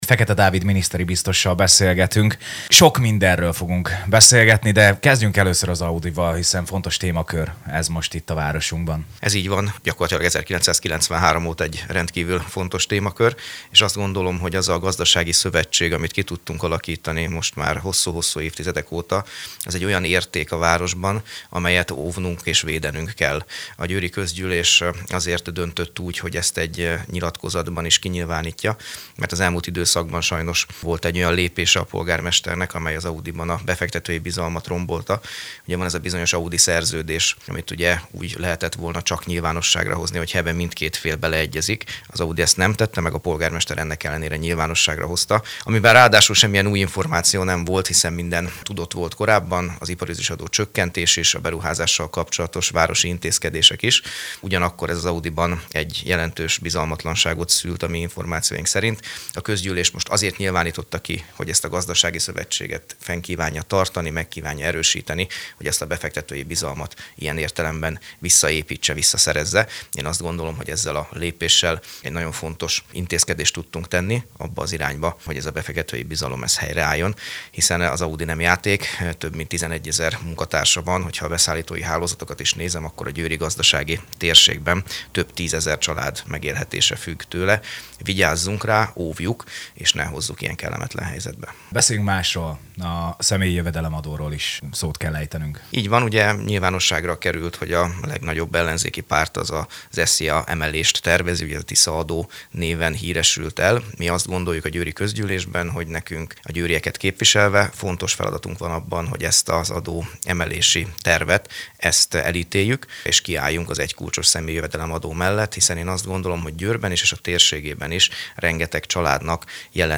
Fekete_David_miniszteri_biztos_volt_a Radio_1_gyori_studiojanak_vendege.mp3